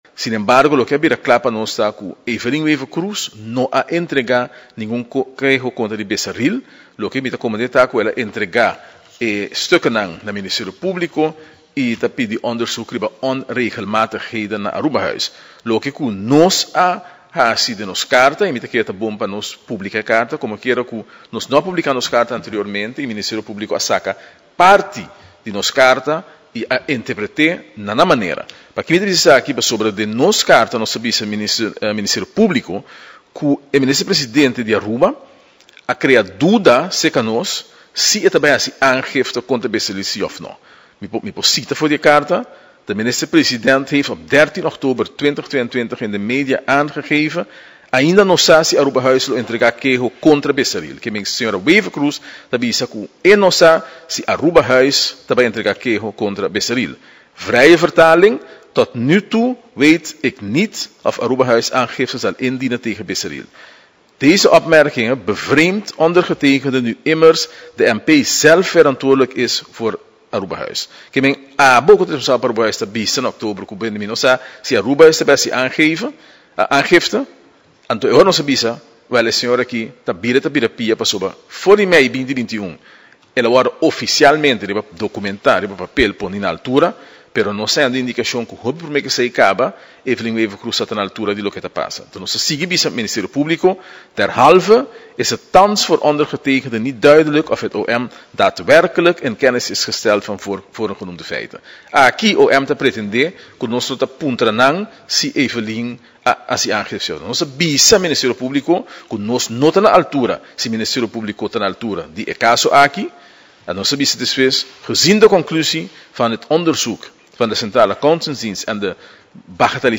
Parlamentario Arthur Dowers den conferencia di prensa ta trece padilanti cu e caso di Arubahuis ta un situacion cu a explota. Segun Dowers prome minister a crea duda si e ta bay haci un denuncia realmente, pesey mes como parlamentario a bay ministerio publico.